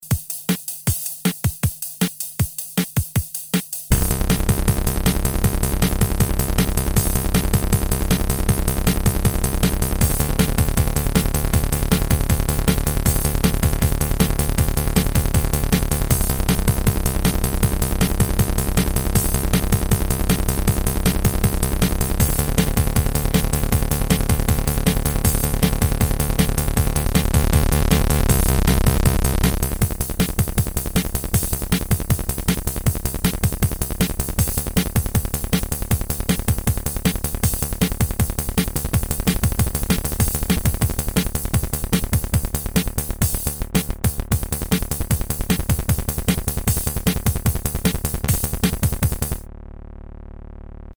Italian preset drum machine based on really lo-fi 8-bits samples with rhythm patterns.
sync mode with Juno60 arpeggiator